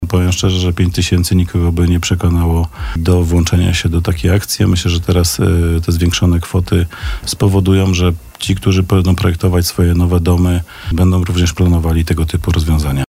– Wymiernych efektów, takie jakie byśmy chcieli, nie ma – odpowiadał na naszej antenie Przemysław Kamiński.
– Będziemy chcieli zmienić dotacje [ich wysokość – red.], żeby kwota wynosiła: w przypadku dachu ekstensywnego – z 5 do 20 tys. zł, w przypadku dachu intensywnego – z 10 tys. na 40 tys. zł – przytaczał te kwoty na naszej antenie wiceprezydent miasta.